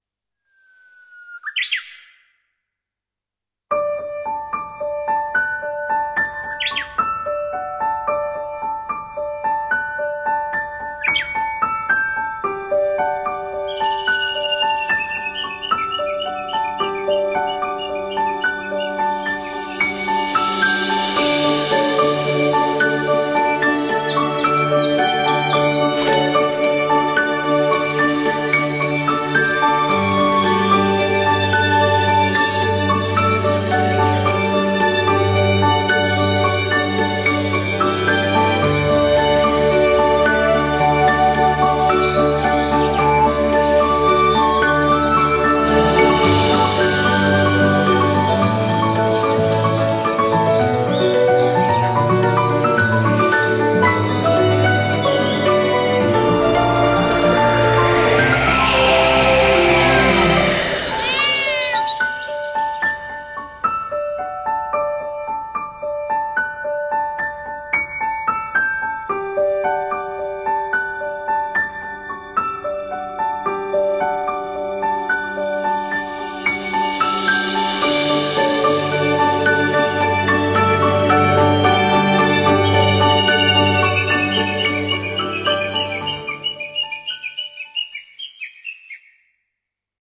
CM風声劇「桜前線春告道中」